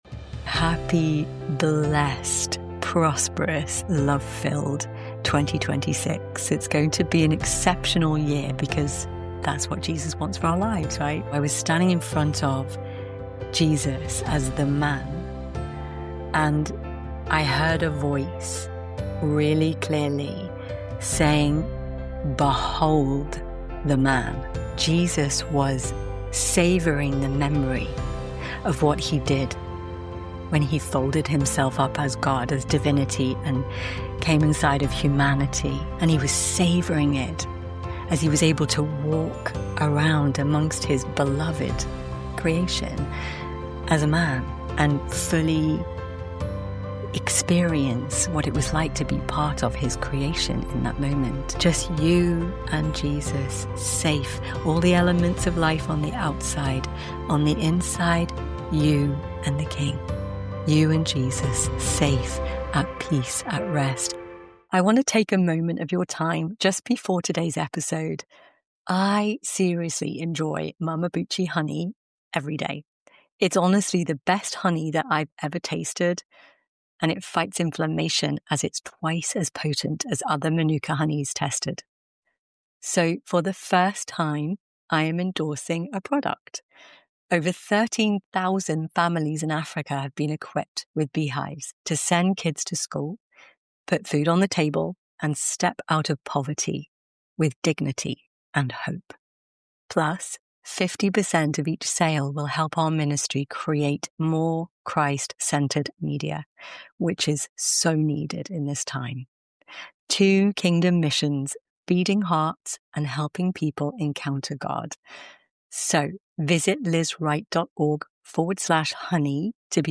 1 Guest Host: Kelsey Hightower - Are CI/CD and GitOps Just Making Things Harder? 30:18